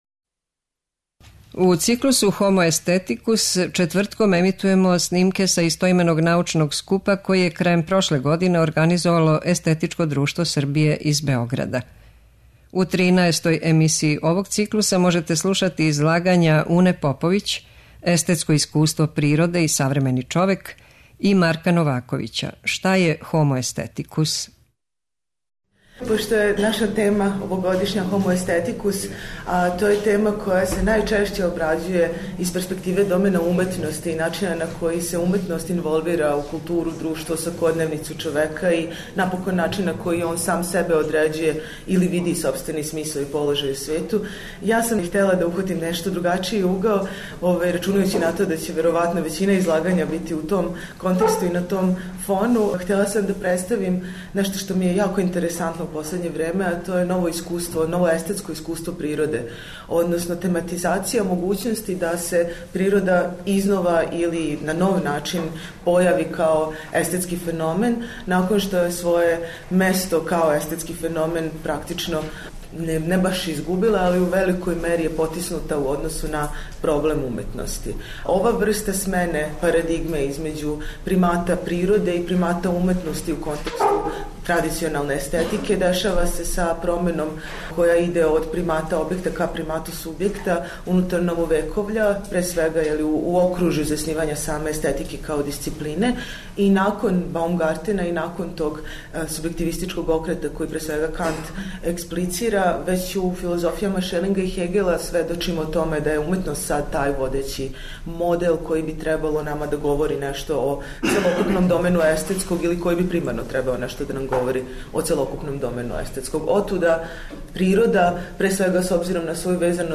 Научни скупoви
преузми : 12.78 MB Трибине и Научни скупови Autor: Редакција Преносимо излагања са научних конференција и трибина.